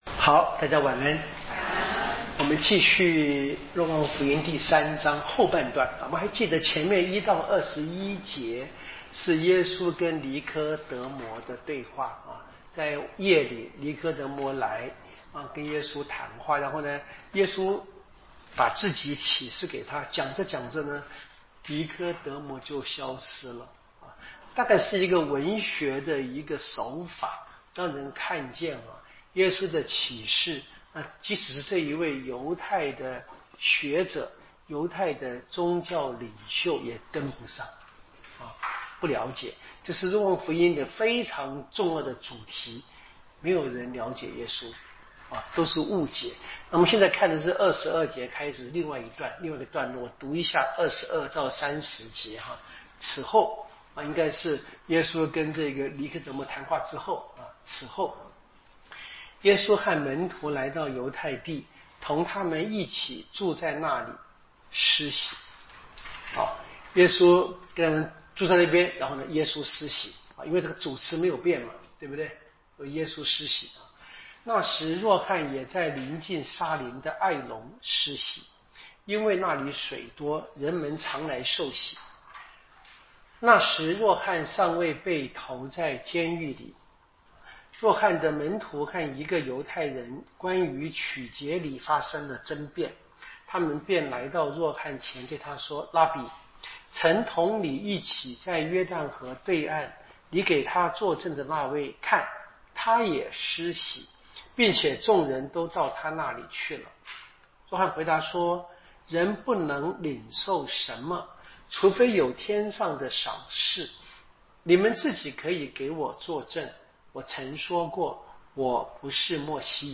【圣经讲座】《若望福音》